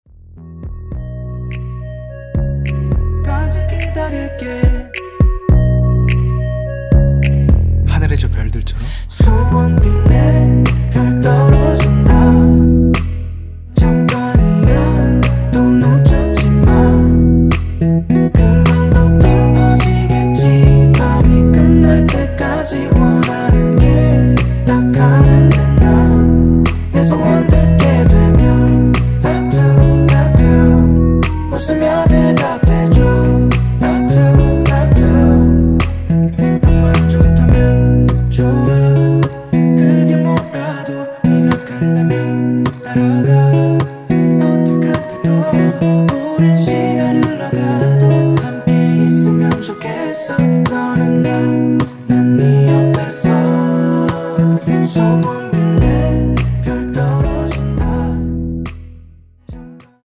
원키 멜로디와 코러스 포함된 MR입니다.(미리듣기 확인)
앞부분30초, 뒷부분30초씩 편집해서 올려 드리고 있습니다.
중간에 음이 끈어지고 다시 나오는 이유는